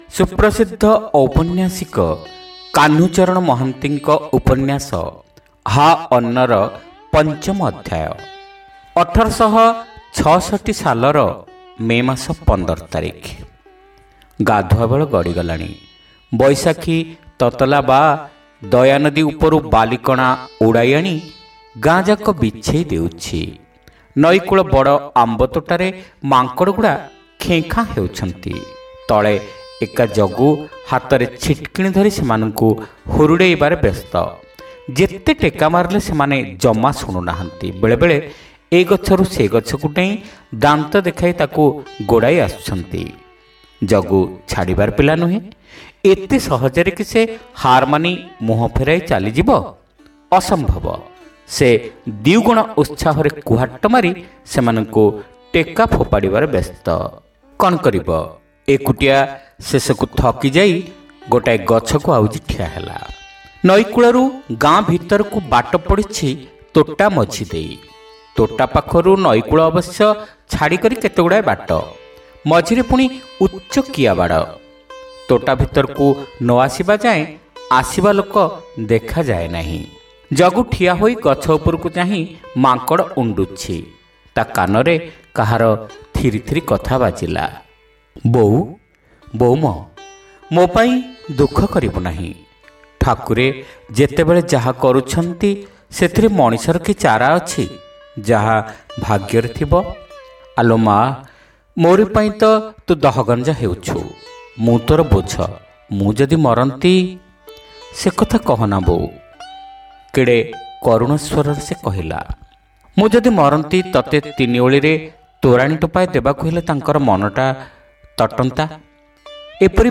ଶ୍ରାବ୍ୟ ଉପନ୍ୟାସ : ହା ଅନ୍ନ (ପଞ୍ଚମ ଭାଗ)